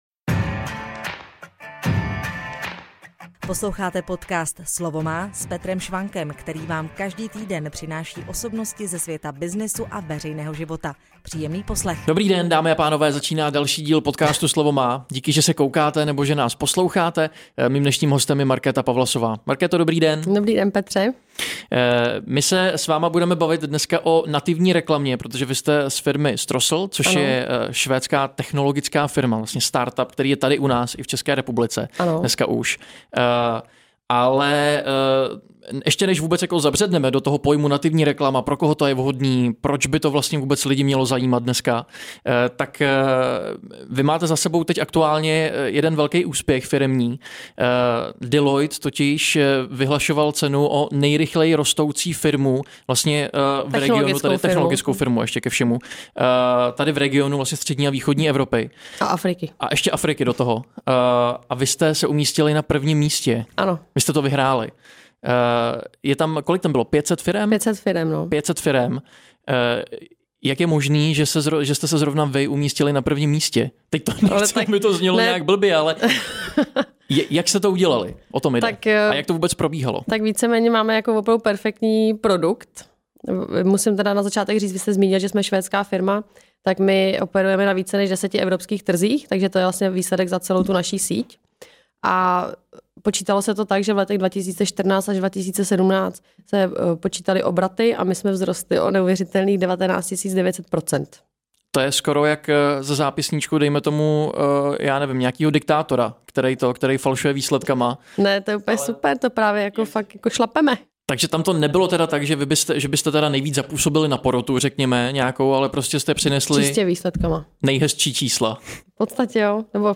Dozvíte se v rozhovoru.